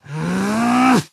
newspaperZombieAngry.ogg